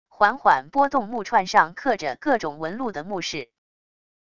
缓缓拨动木串上刻着各种纹路的木饰wav音频